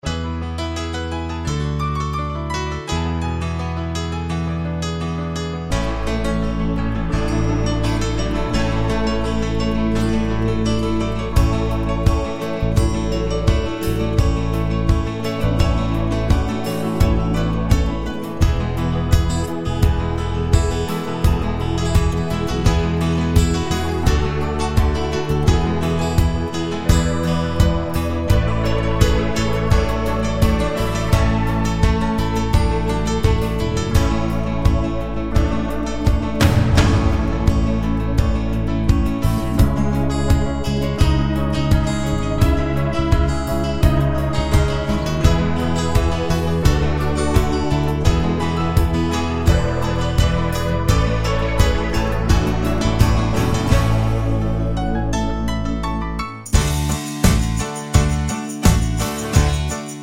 no Backing Vocals no outro Pop (1970s) 4:41 Buy £1.50